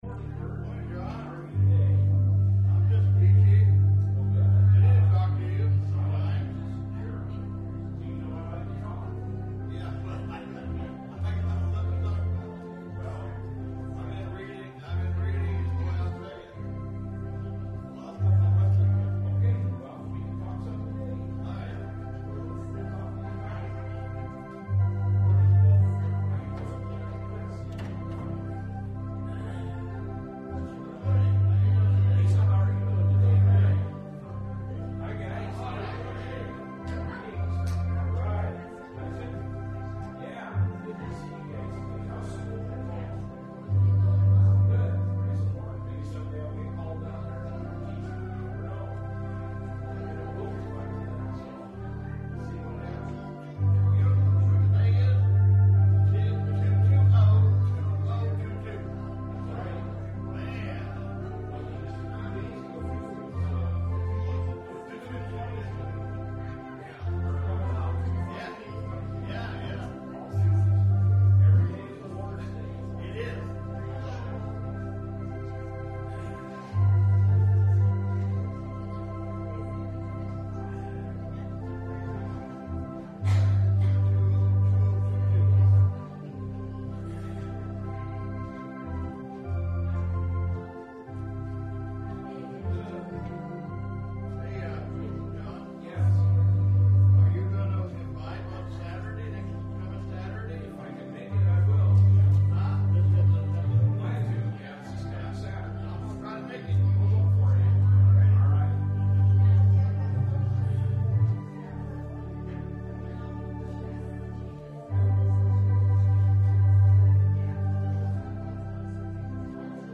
Service Type: Sunday Morning Service Topics: Christian Living , Love , Valentines Day